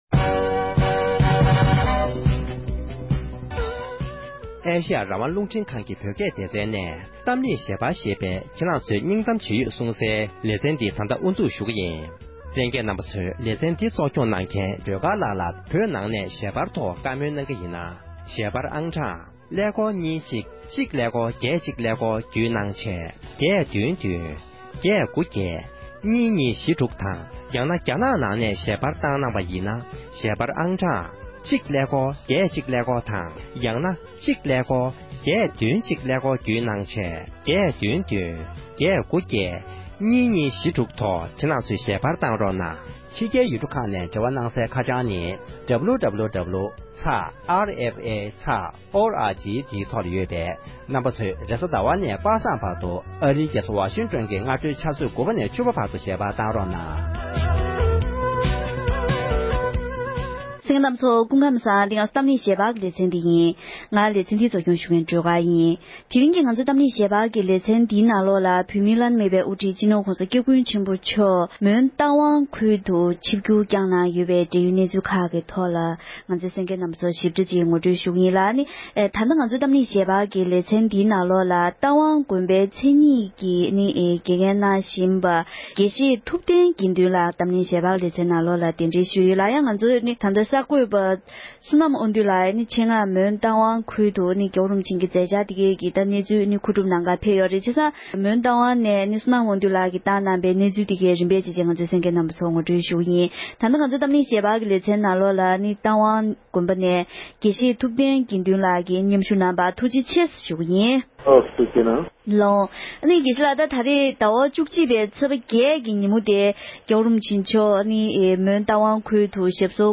༄༅༎དེ་རིང་གི་གཏམ་གླེང་ཞལ་པར་གྱི་ལེ་ཚན་ནང་དུ་བོད་མིའི་དབུ་ཁྲིད་སྤྱི་ནོར་༸གོང་ས་༸སྐྱབས་མགོན་ཆེན་པོ་མཆོག་གིས་རྟ་ཝང་ཁུལ་དུ་ཆིབས་བསྒྱུར་བསྐྱངས་པའི་འབྲེལ་ཡོད་གནས་ཚུལ་དང་དེ་བཞིན་རྒྱ་དཀར་ནག་གཉིས་ཀྱི་རྩོད་རྙོག་སོགས་ཀྱི་སྐོར་ལ་བགྲོ་གླེང་ཞུས་པའི་དུམ་མཚམས་དང་པོ་དེར་གསན་རོགས༎